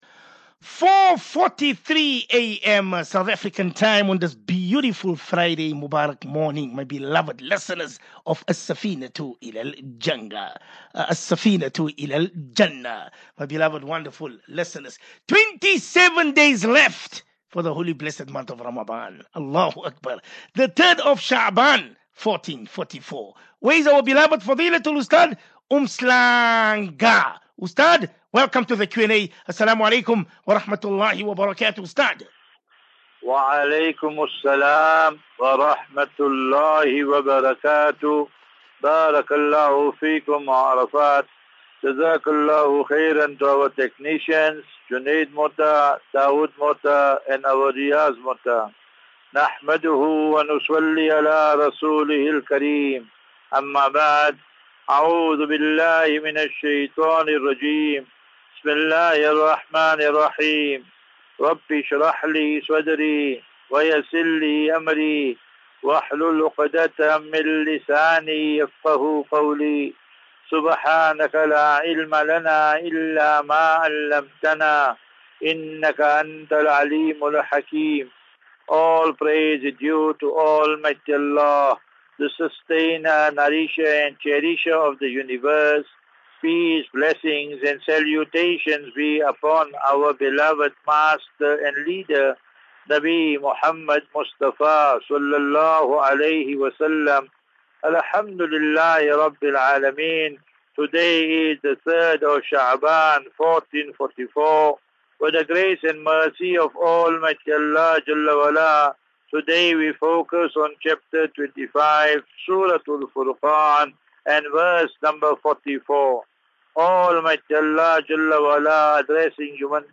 View Promo Continue Install As Safinatu Ilal Jannah Naseeha and Q and A 24 Feb 24 Feb 23 Assafinatu Illal Jannah 37 MIN Download